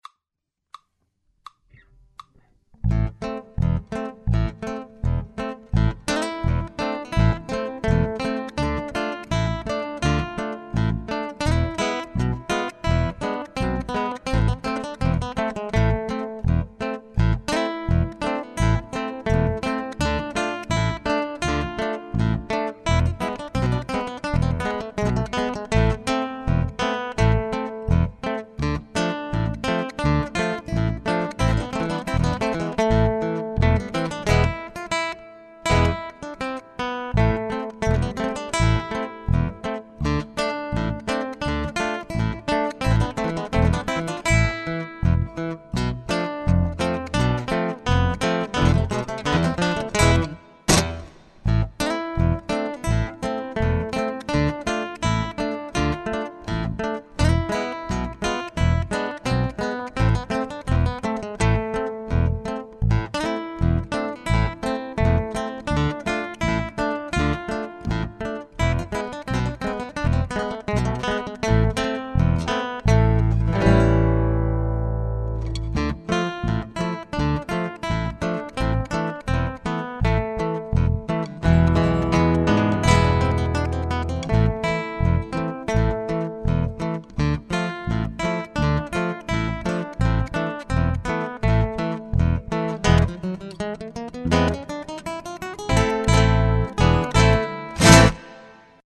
guitar ensembles in a variety of styles